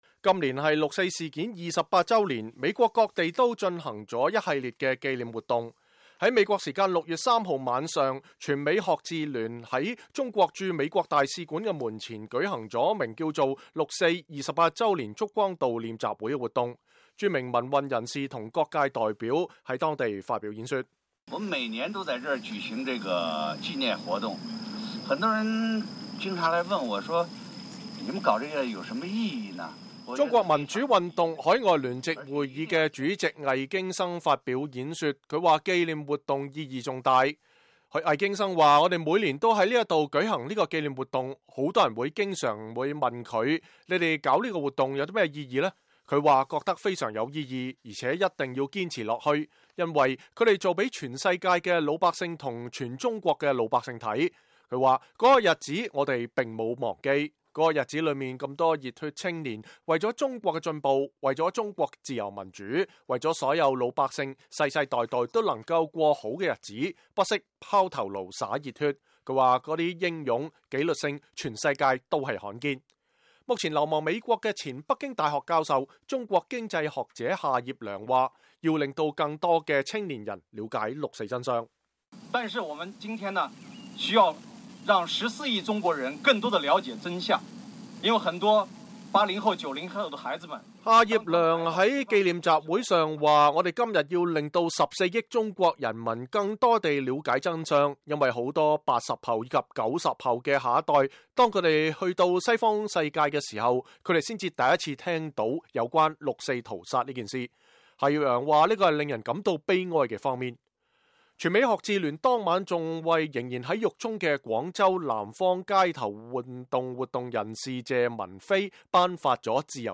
今年是六四事件28周年，美國各地都進行了一系列紀念活動。6月3日晚，全美學自聯在中國駐美大使館的門前進行了名為“六四”28周年燭光追悼會的活動，著名民運人士和各界代表發表演講。